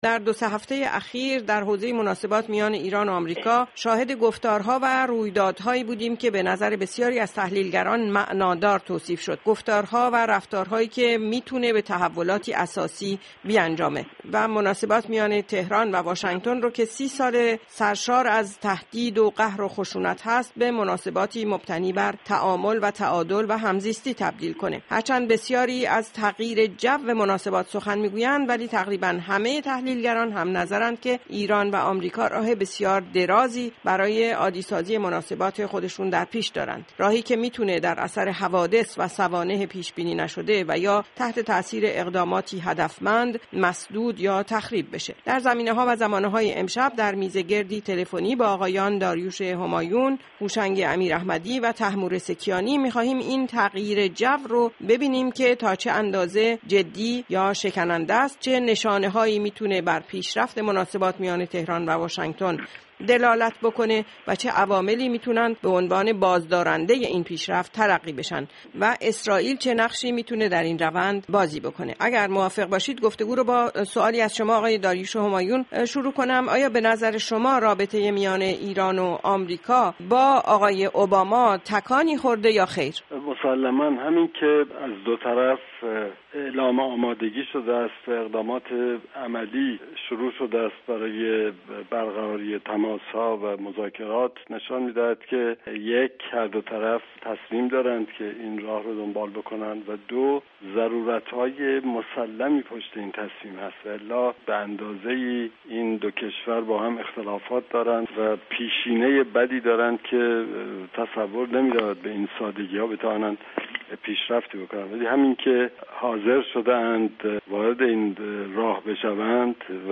table_ronde_Iran-USA-_Israel.mp3